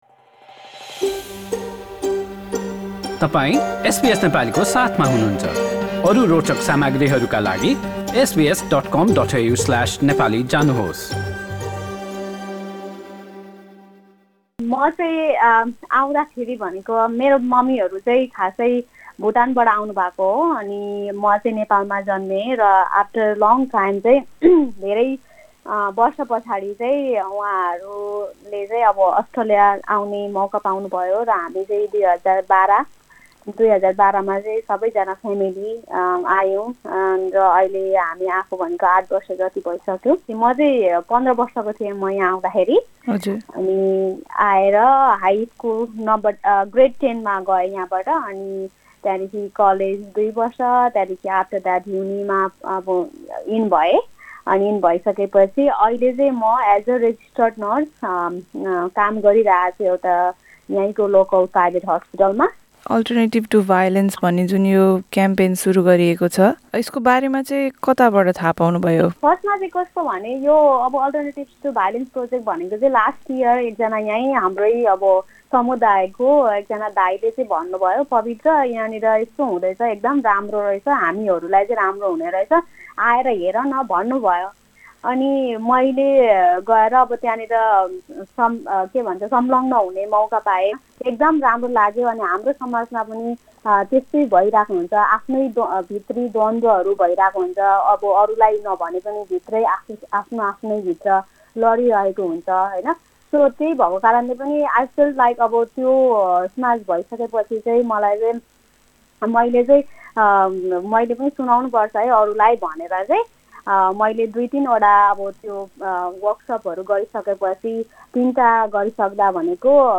A text version of this news report is available in the Nepali language section of our website.